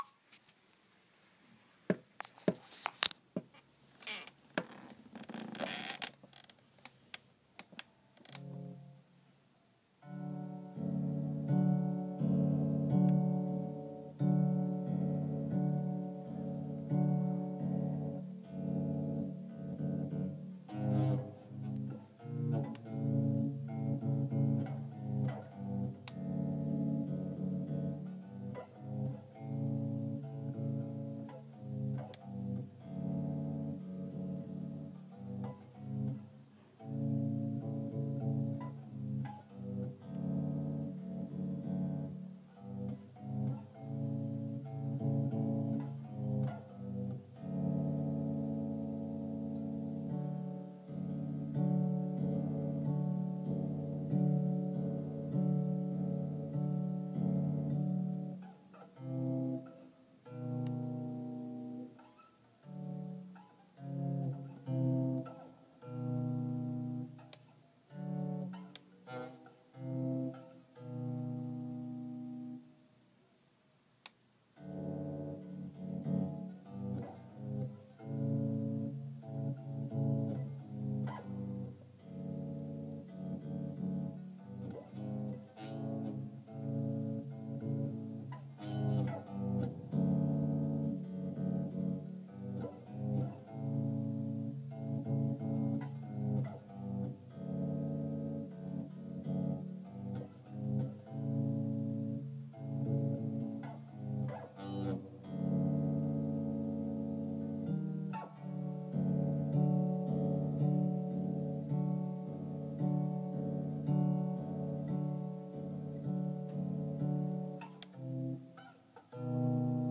Sorry if the volume is low. This is me on my Ibanez 5 string bass.